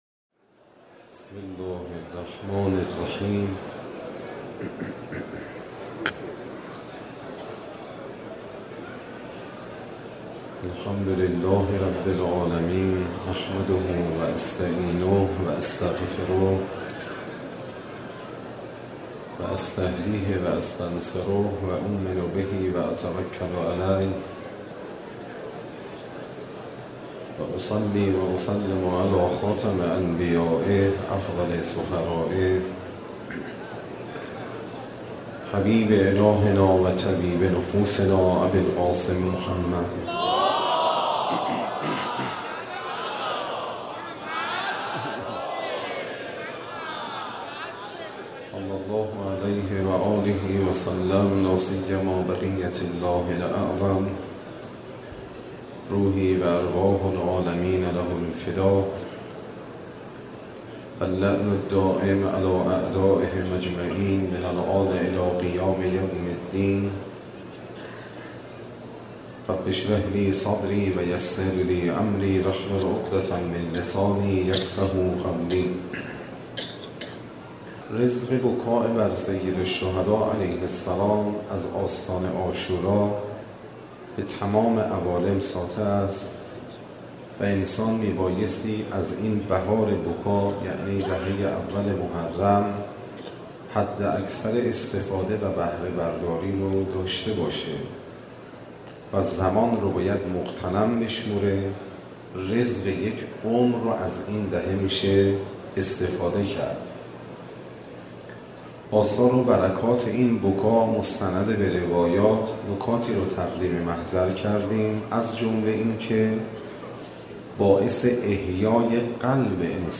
Moharrame 93, Shabe 06, Sokhanrani.mp3